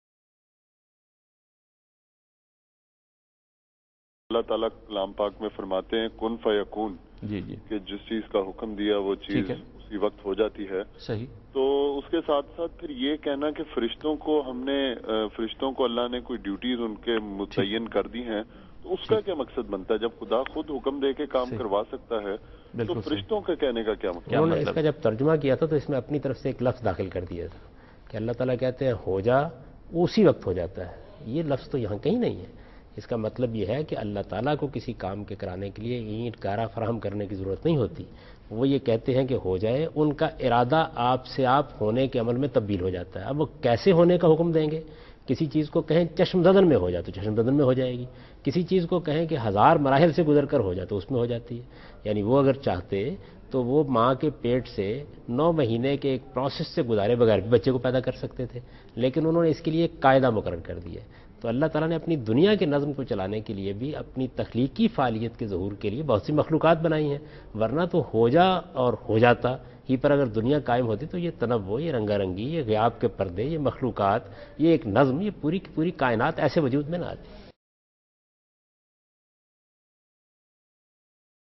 Category: TV Programs / Dunya News / Deen-o-Daanish /
Javed Ahmad Ghamidi answers a question regarding "Correct Translation of the Expression Kun Fayakoon" in program Deen o Daanish on Dunya News.